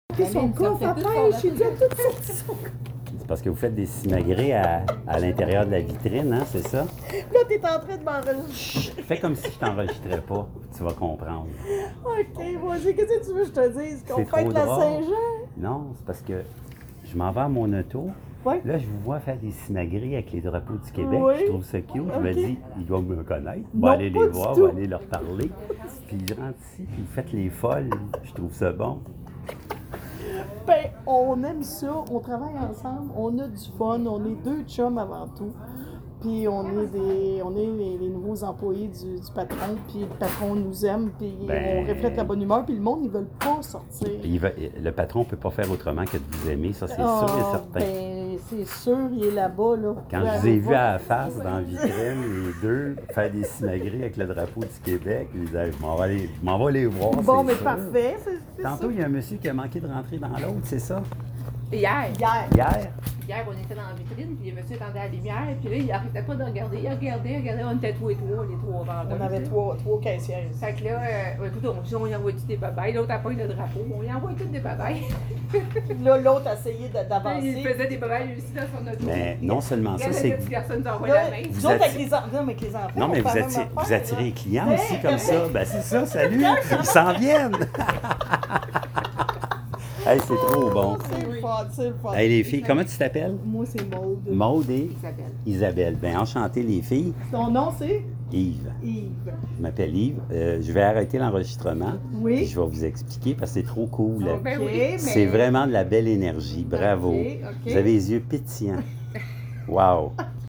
Rue Queen, Rawdon.